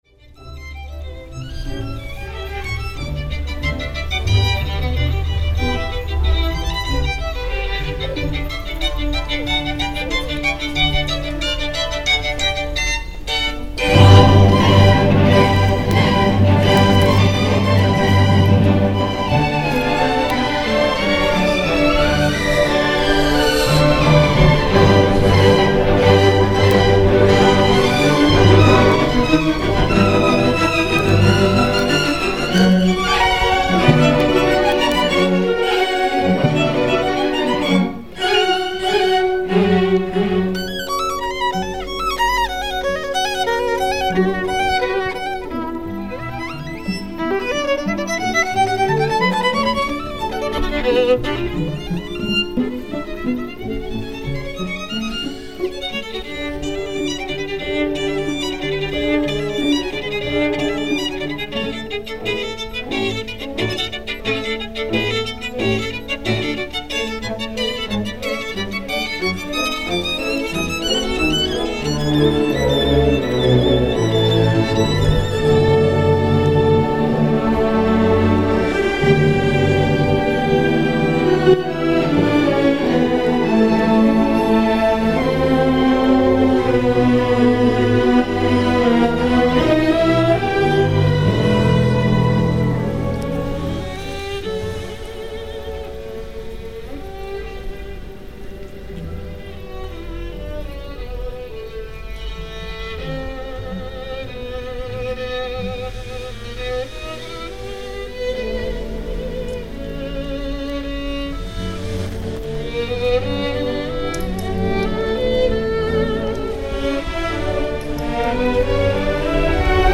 7th August 2002 - Kent County Youth Orchestra playing at Il Fosso
violinist
MP3 file and just listen to his spirited playing.
kent_county_youth_orchestra.mp3